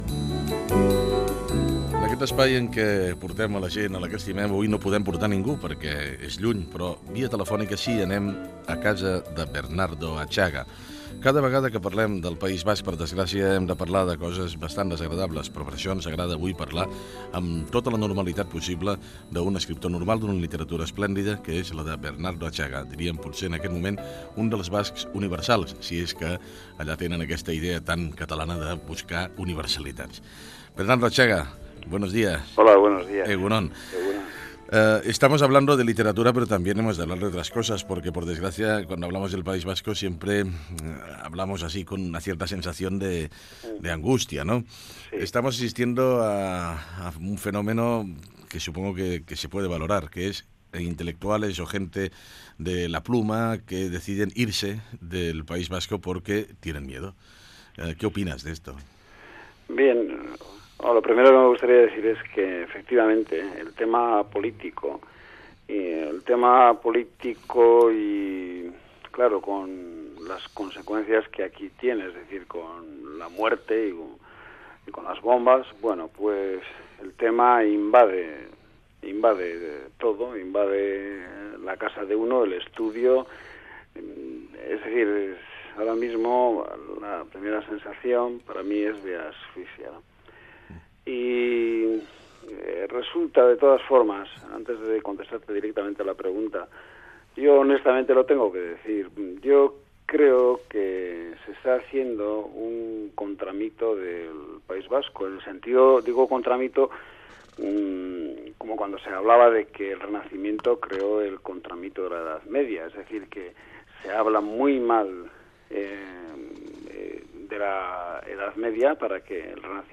Perfil biogràfic i fragment d'una entrevista telefònica a l'escriptor basc Bernardo Atxaga.
Info-entreteniment